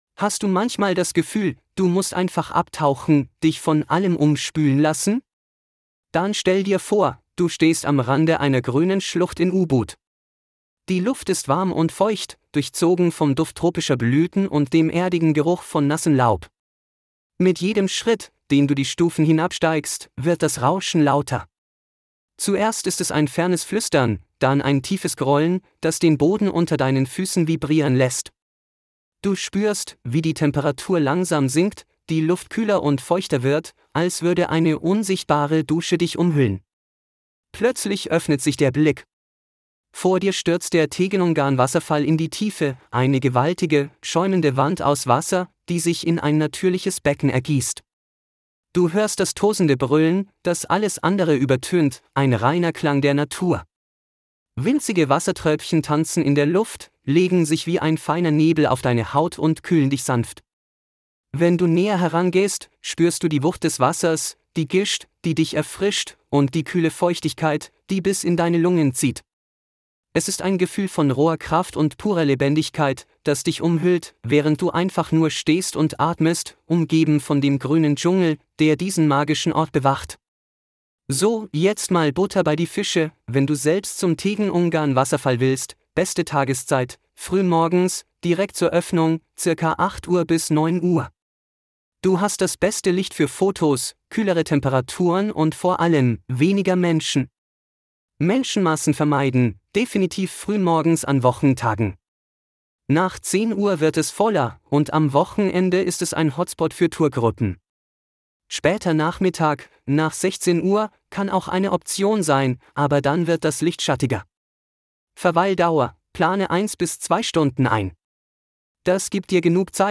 🎧 Verfügbare Audioguides (2) Guide für emotionale Erfahrungen (DE) 🔗 In neuem Tab öffnen Praktische Informationen (DE) 🔗 In neuem Tab öffnen